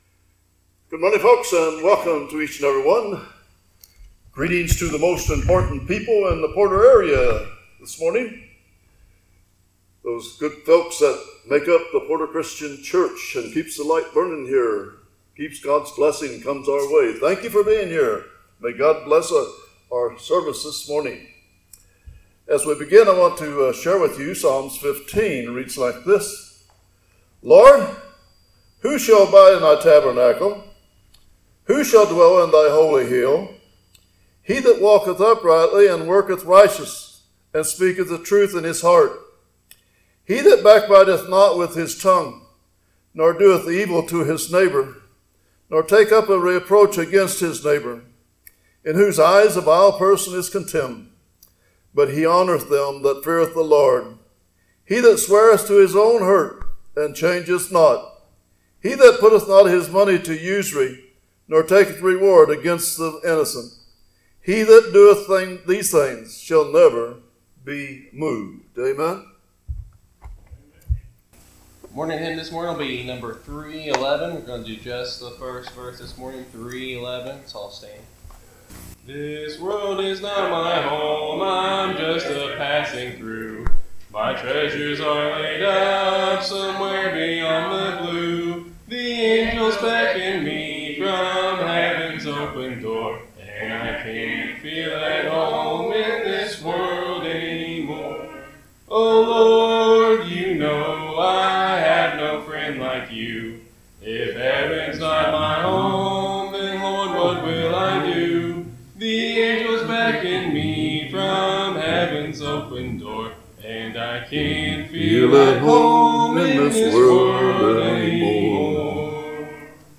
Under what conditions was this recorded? Sundy Morning Worship Service